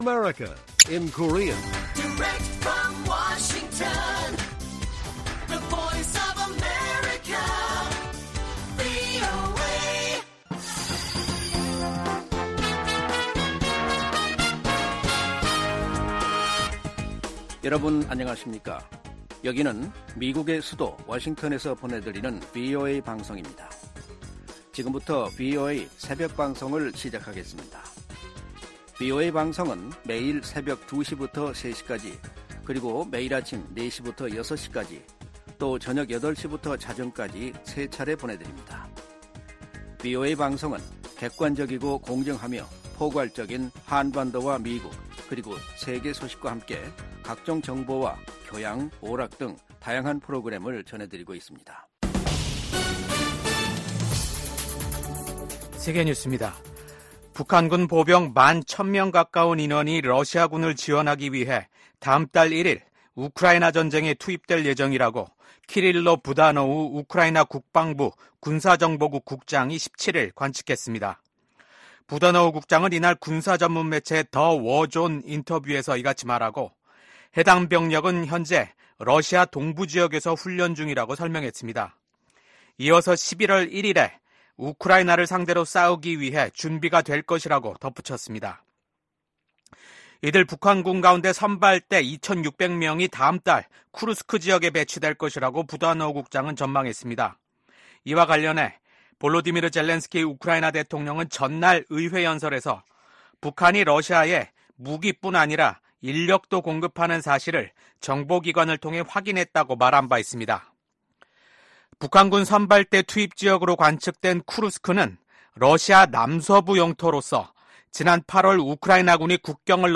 VOA 한국어 '출발 뉴스 쇼', 2024년 10월 19일 방송입니다. 북한이 한국을 헌법상 적대국으로 규정한 가운데 김정은 국무위원장은 전방부대를 방문해 한국을 위협하는 행보를 보였습니다. 윤석열 한국 대통령은 국가안보실, 국방부, 국가정보원 핵심 관계자 등이 참석한 가운데 ‘북한 전투병의 러시아 파병에 따른 긴급 안보회의’를 열어 대응 방안을 논의했다고 대통령실이 전했습니다.